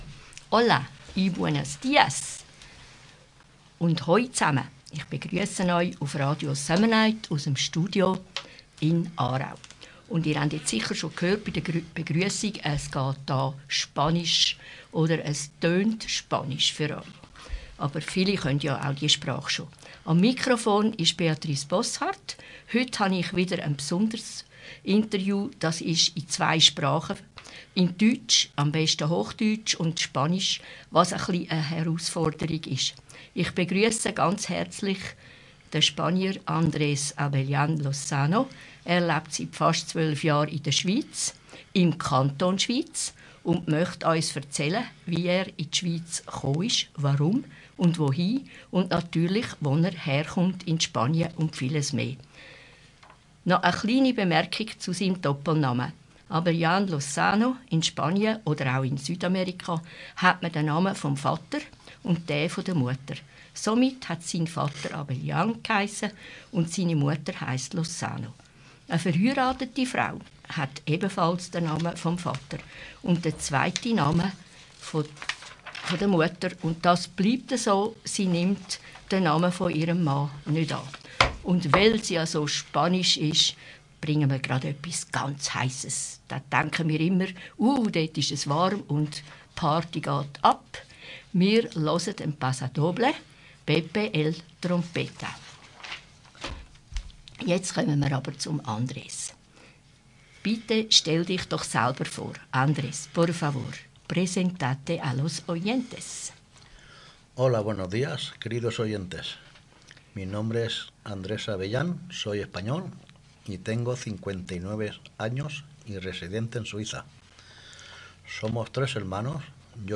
Ein ehrliches Gespräch über Heimat, Arbeit, Integration und die Kunst, auch im Ausland glücklich zu werden.